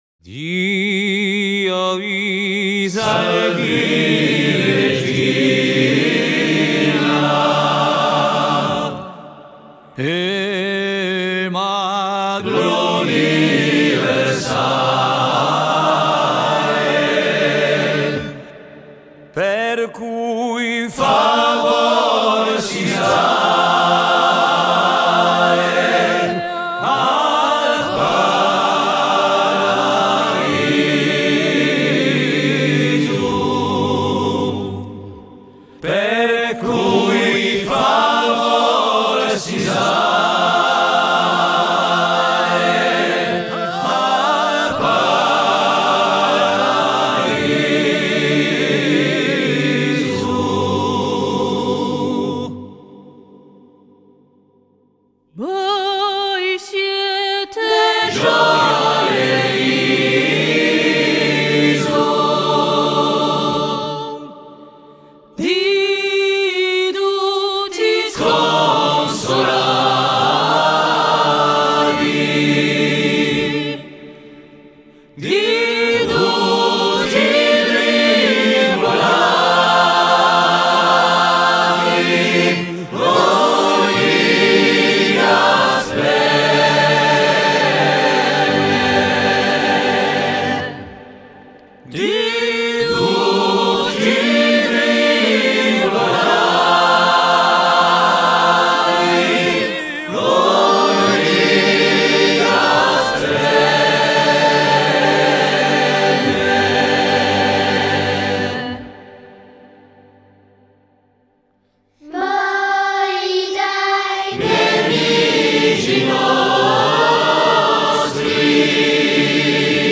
- Oeuvre pour choeur à 3 voix mixtes
- Hymne religieux des corses